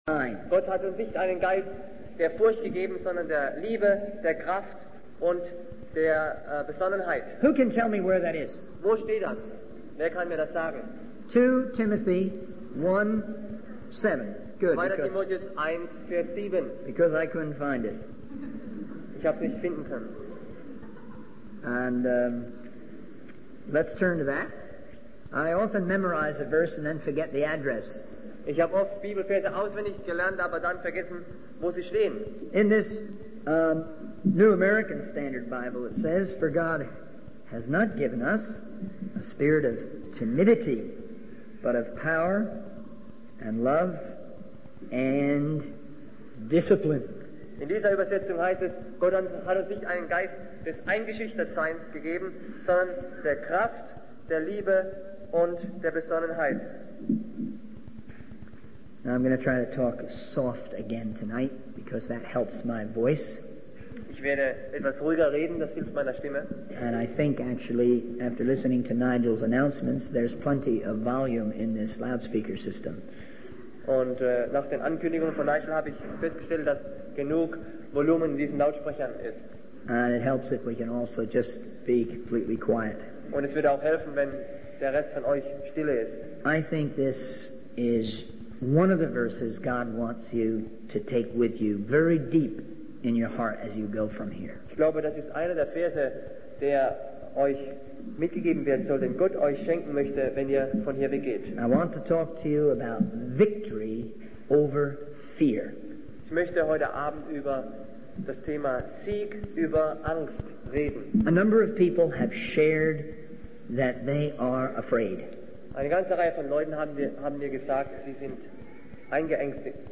In this sermon, the speaker begins by reading a verse from the Bible that speaks of Jesus being pierced and crushed for our sins.